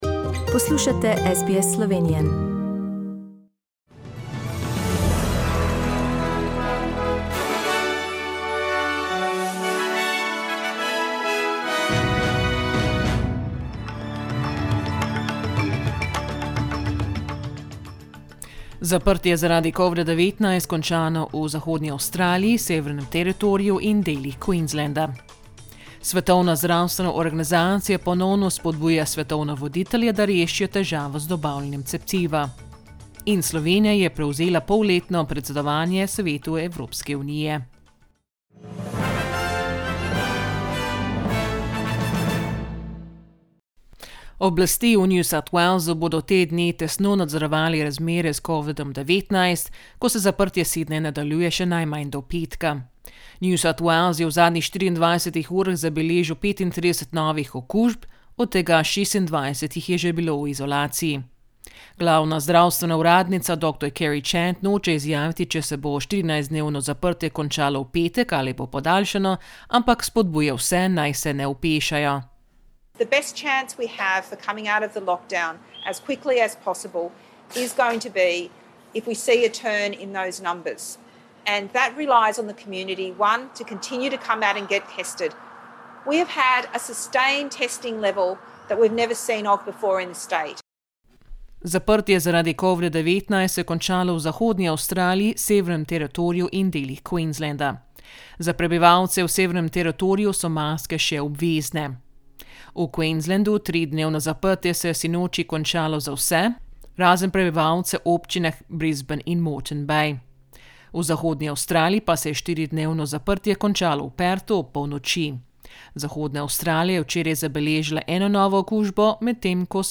Listen to the latest news headlines in Australia from SBS Slovenian radio.